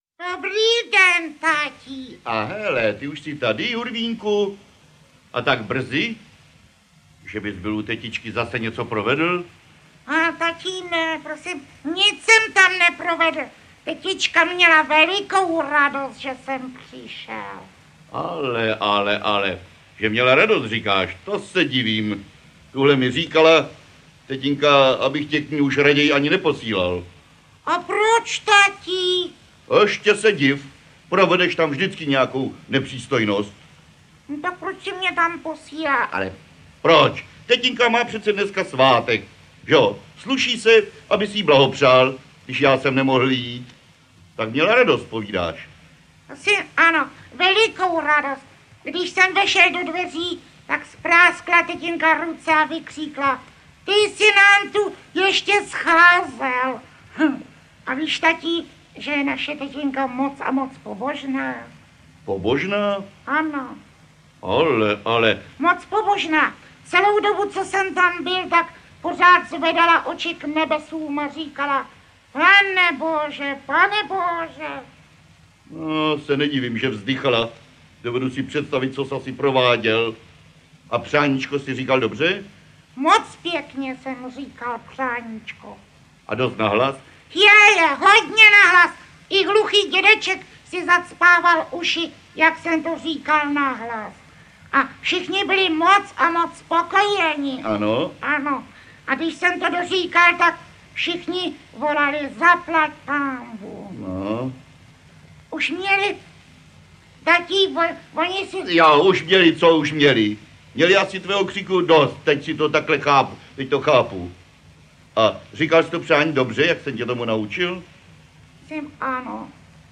Ať žije Hurvínek! audiokniha
Můžete se tu zaposlouchat do zábavných příběhů v podání všech jeho interpretů - prof. Josefa Skupy, Miloše Kirschnera a Martina Kláska. Na této kompilaci najdete nejlepší scénky těch nejlepších autorů.